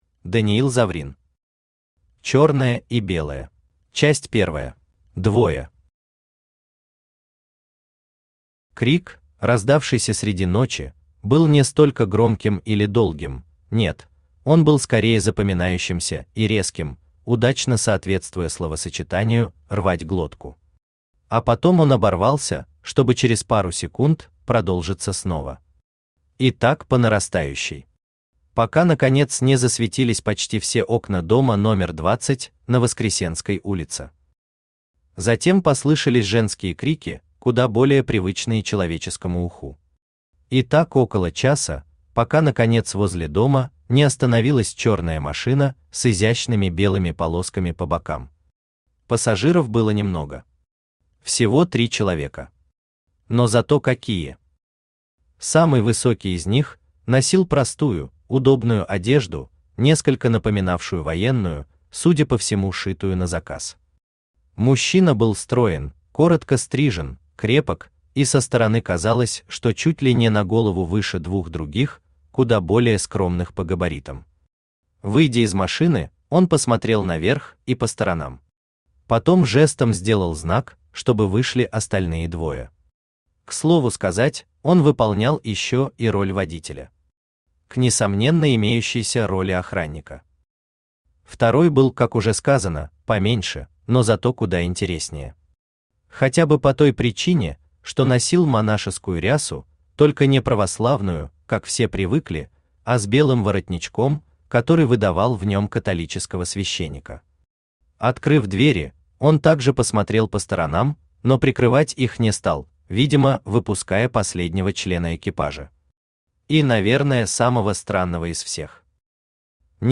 Аудиокнига Черное и Белое | Библиотека аудиокниг
Aудиокнига Черное и Белое Автор Даниил Заврин Читает аудиокнигу Авточтец ЛитРес.